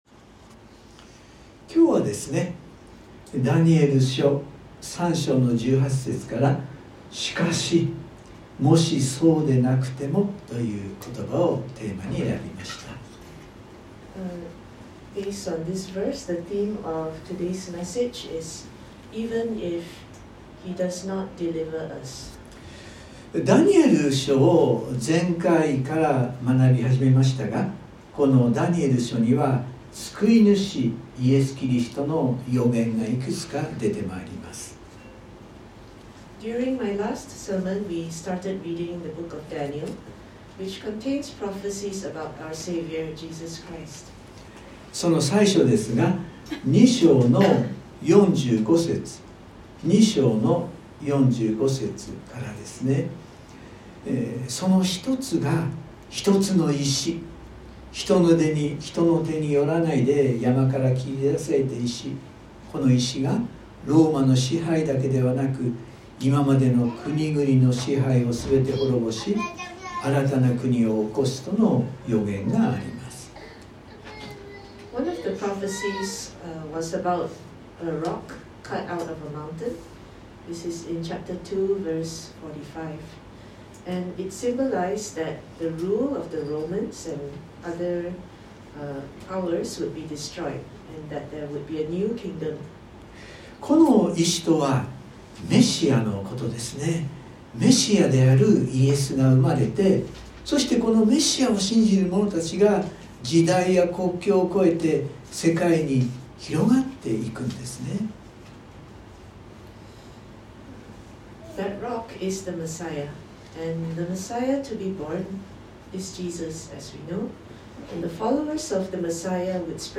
(Sunday worship recording)